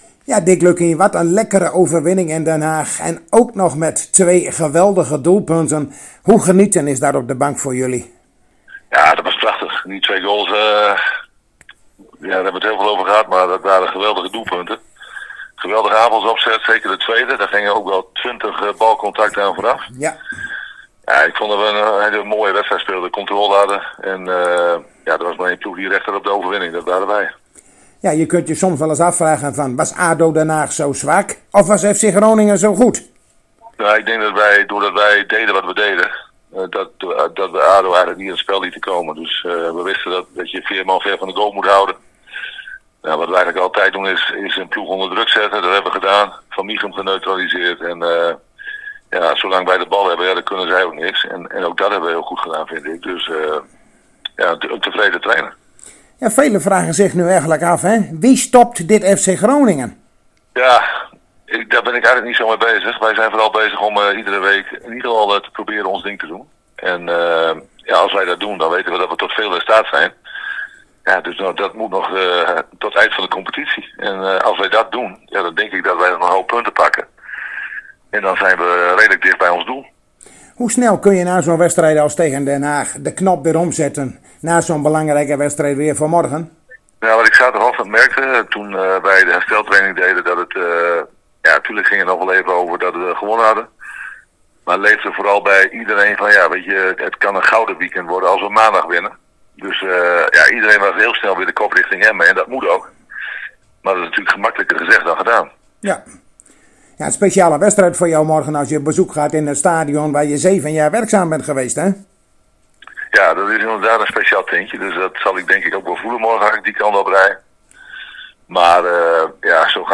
In gesprek met Dick Lukkien over de wedstrijd FC Emmen - FC Groningen
Zojuist spraken wij weer met trainer Dick Lukkien en keken met hem even terug op de overwinning in Den Haag en we keken vooruit op de wedstrijd van morgenmiddag op De Oude Meerdijk tegen FC Emmen. Een speciale wedstrijd voor de trainer nadat hij zeven jaar werkzaam was bij de Drentse club.